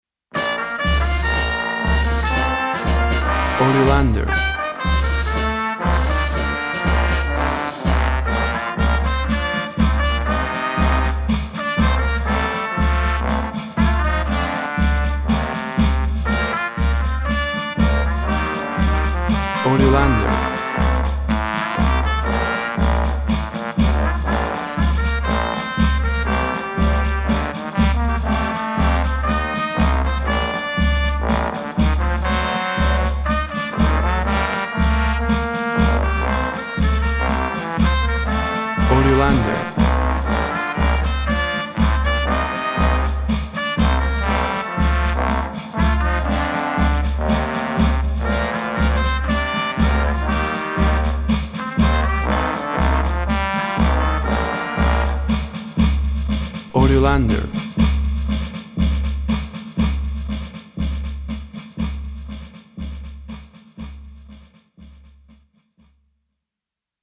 WAV Sample Rate 24-Bit Stereo, 44.1 kHz
Tempo (BPM) 120